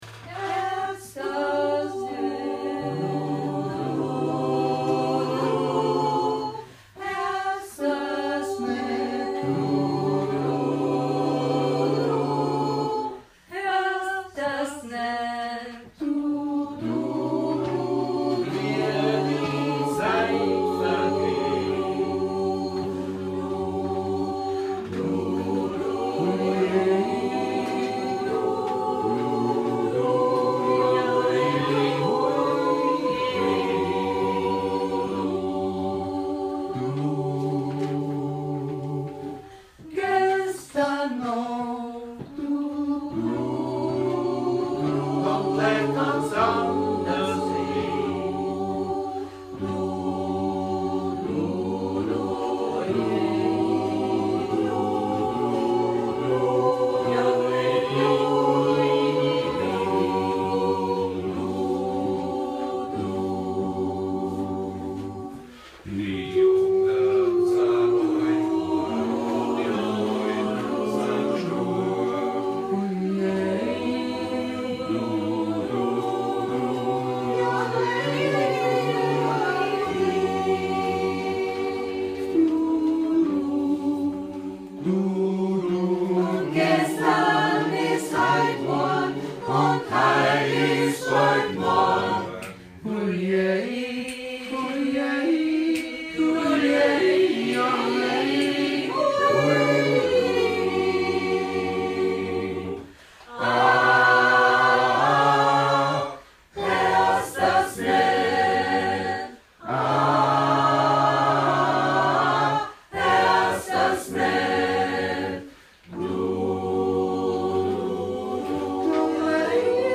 Probe 17.01.2017